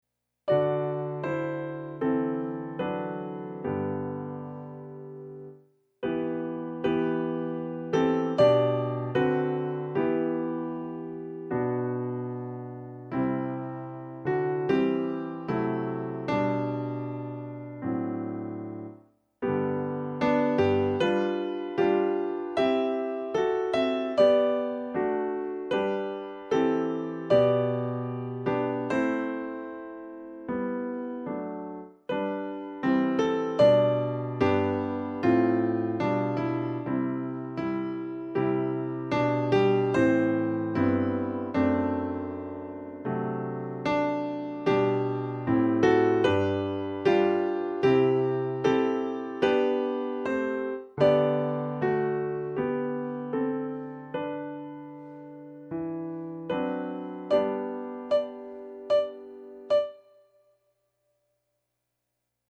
Verse 2 - chorus accomp - MP3